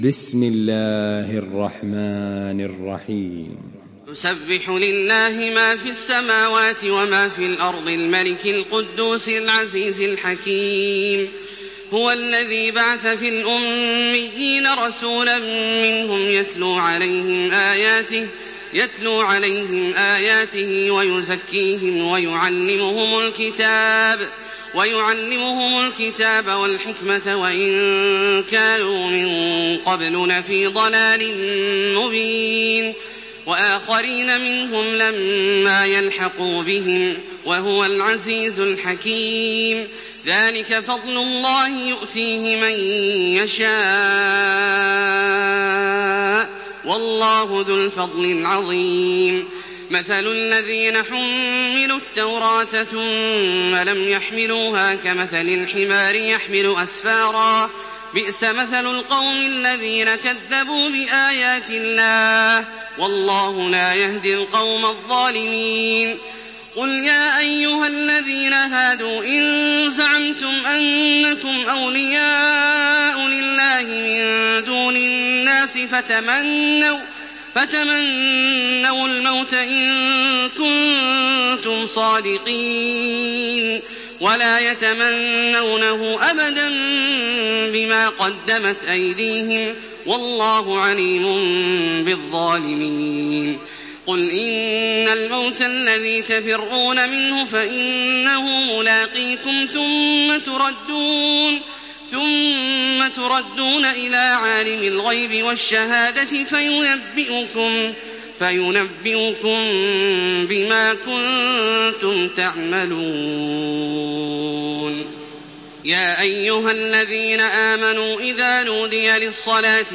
تراويح الحرم المكي 1428
مرتل